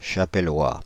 Ääntäminen
Ääntäminen France (Île-de-France): IPA: [ʃa.pɛ.lwa] Haettu sana löytyi näillä lähdekielillä: ranska Käännöksiä ei löytynyt valitulle kohdekielelle.